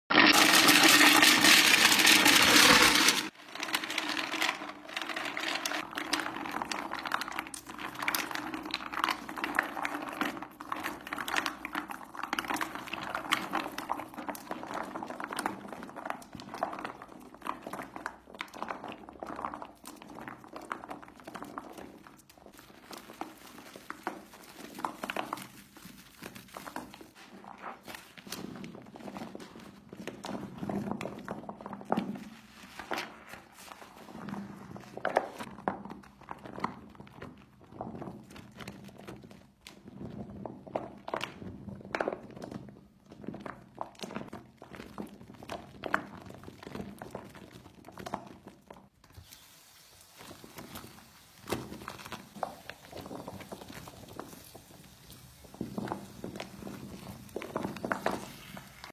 Super soapy asmr insane tingles